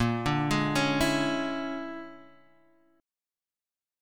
A#7#9b5 chord